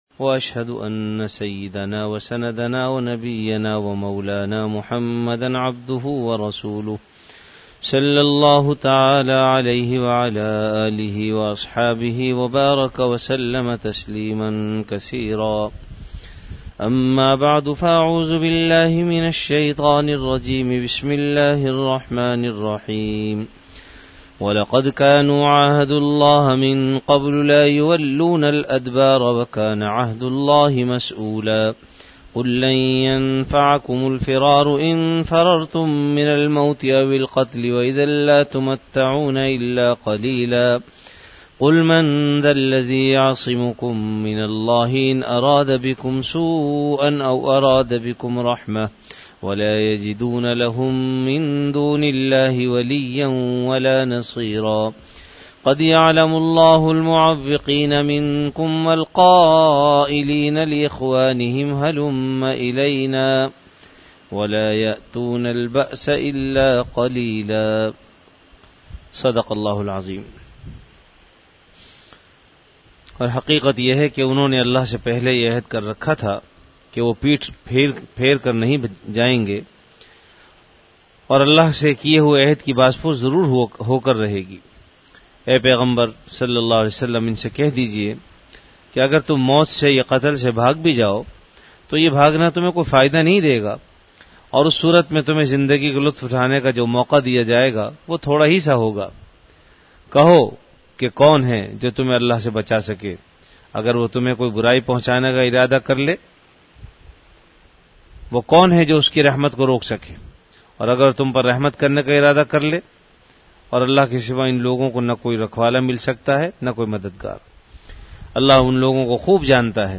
Dars-e-quran · Jamia Masjid Bait-ul-Mukkaram, Karachi